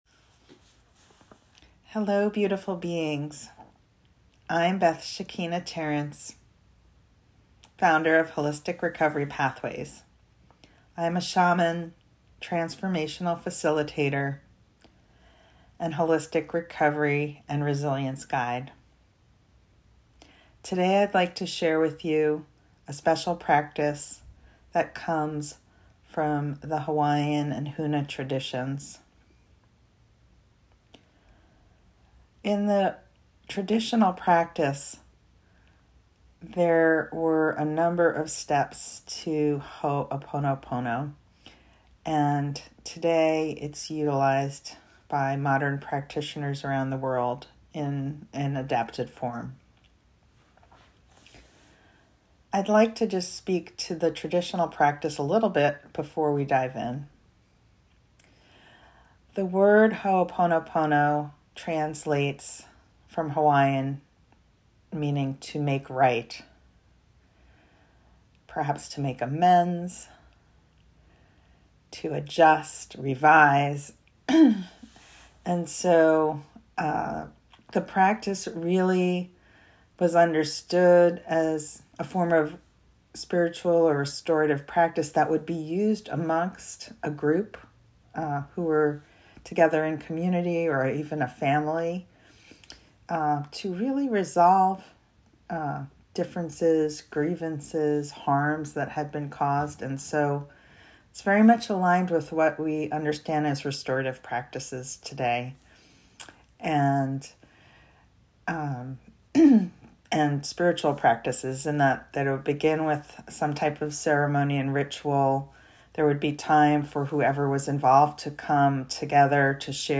Awakening Resilience ~ Embracing the Ho’Oponopono Way Handout 8.34 MB Ho'Oponopono Intro & Guided Meditation (Audio) 00:19:57